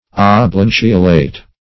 Oblanceolate \Ob*lan"ce*o*late\, a. [Pref. ob- + lanceolate.]